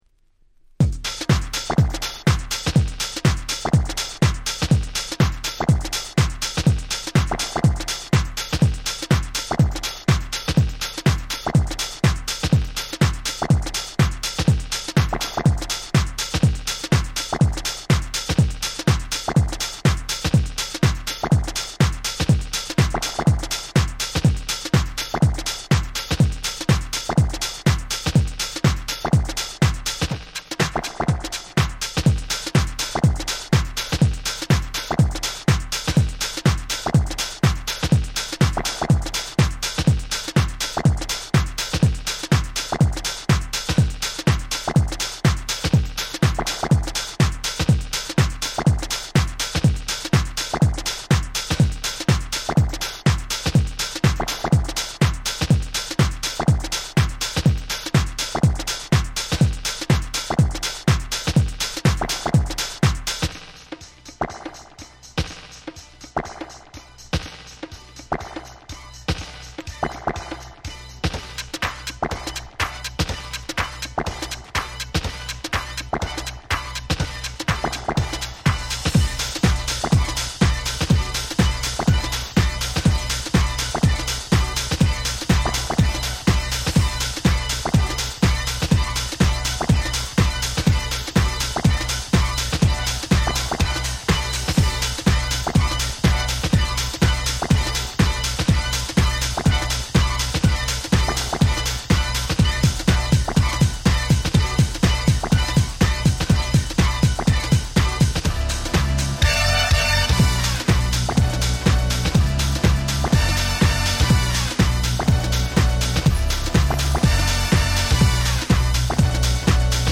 98' Super Hit Vocal House !!